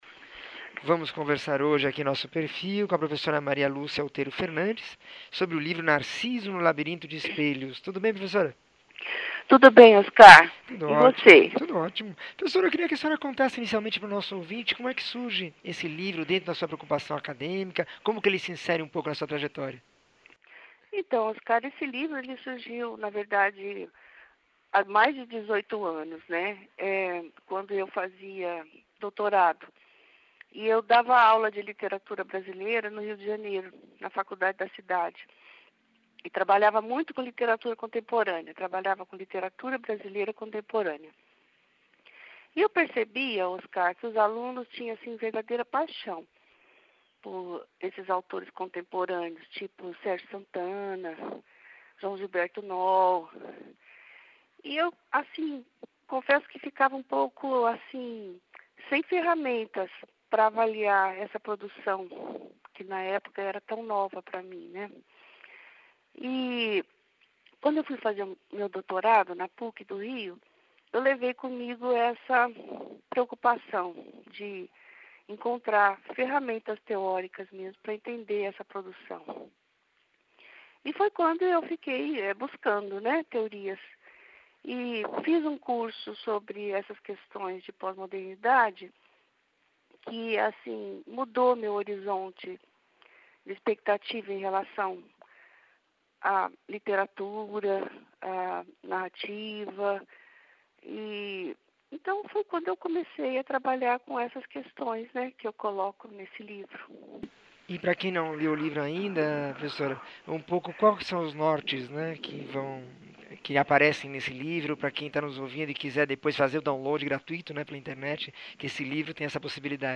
entrevista 1444